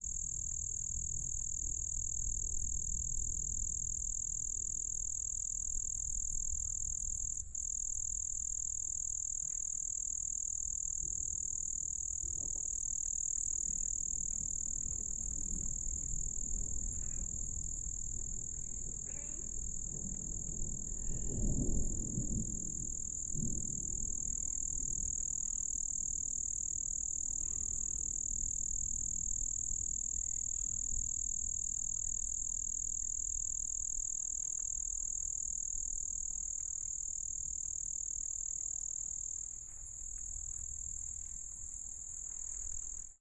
加纳 " AMB加纳雷霆，蟋蟀，Vox LB
描述：加纳Biakpa附近的莱特雷暴与蟋蟀
Tag: 蟋蟀 非洲 迅雷 加纳